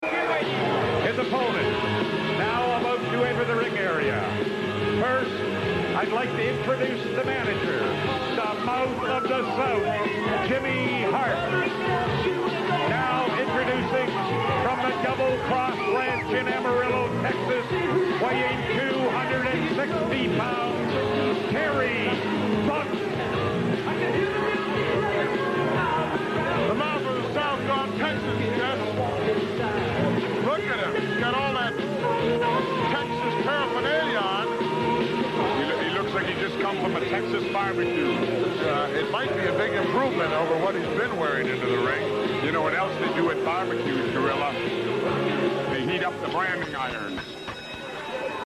entrance music